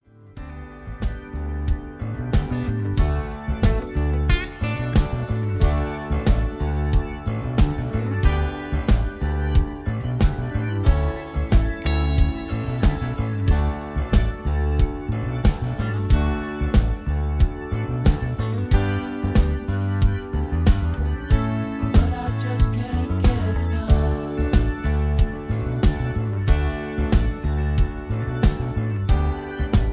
backing tracks
easy listening